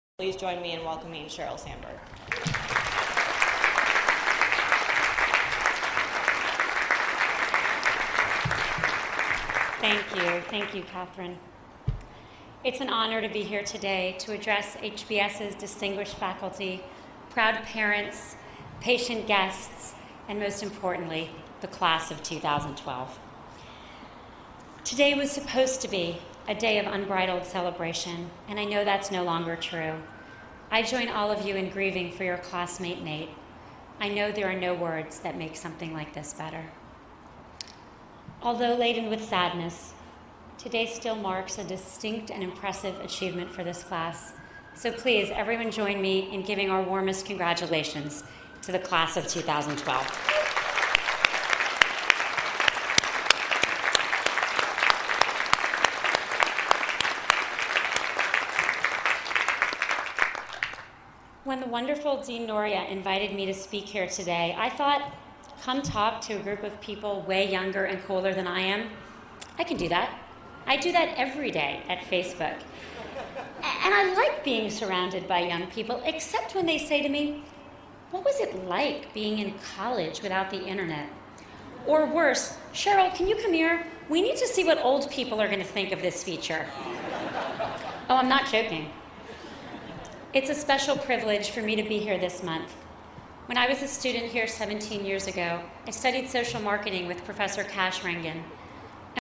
公众人物毕业演讲第176期:桑德伯格2012哈佛商学院(1) 听力文件下载—在线英语听力室